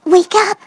synthetic-wakewords
ovos-tts-plugin-deepponies_Fluttershy_en.wav